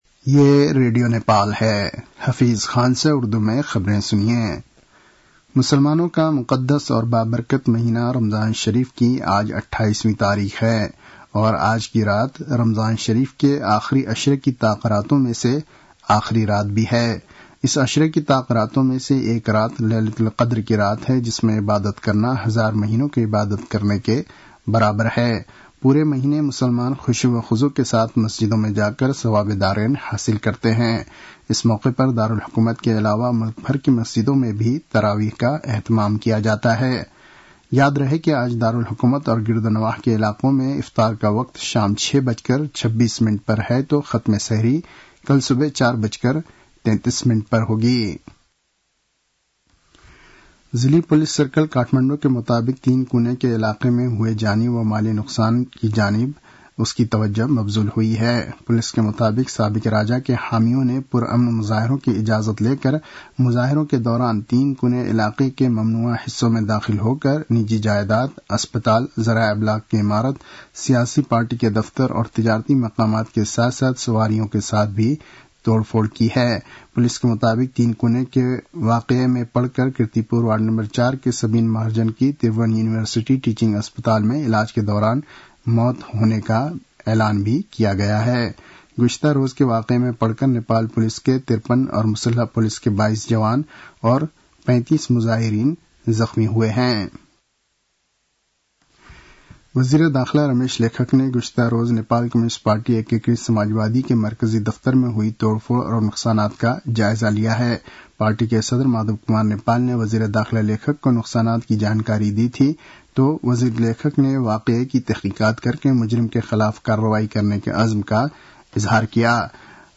उर्दु भाषामा समाचार : १६ चैत , २०८१